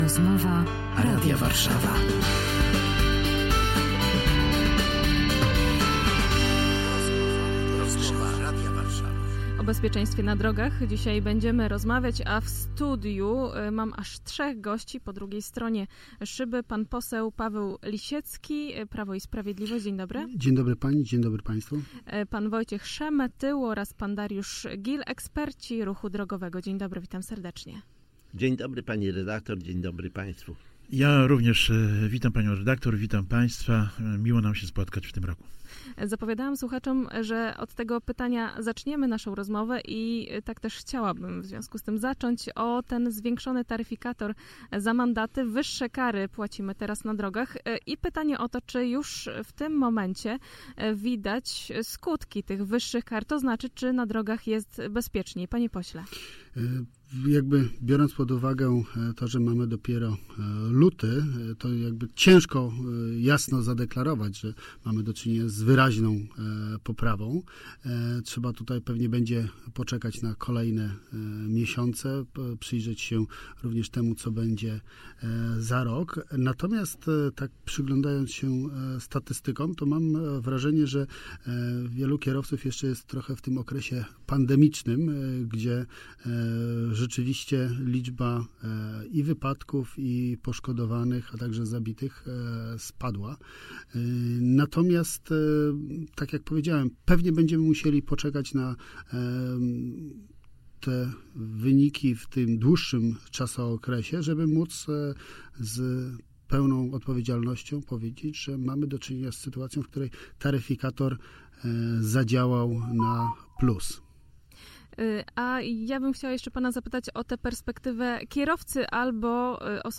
ROZMOWA RADIA WARSZAWA